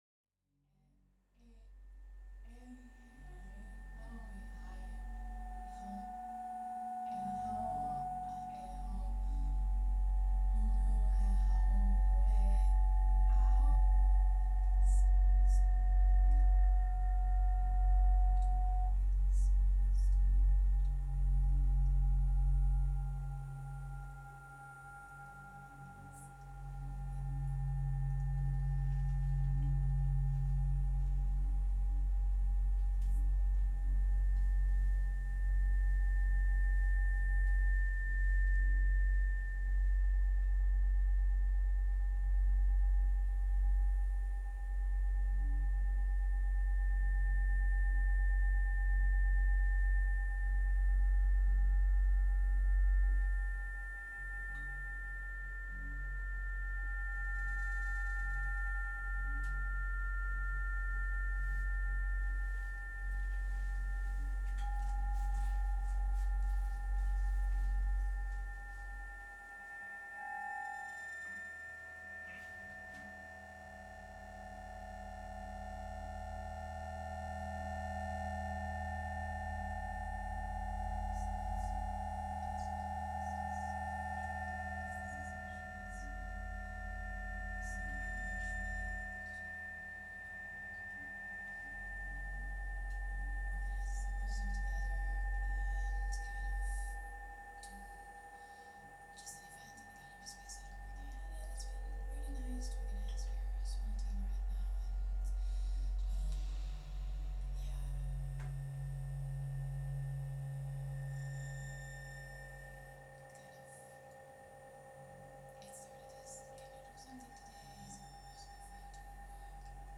_performance excerpt
Experimental opera
The interplay between voice and electronics unfolds in a semi-improvised space, fostering an ambiguous exchange between acting and performing, where the boundaries of meaning, emotion, and human connection become fluid and undefined. Meanwhile, the scenographic stage and sonic spatialization create an immersive, multi-sensory situation, dynamically transforming the space into a living, breathing instrument.
20-10-24 TAM, Bulgaria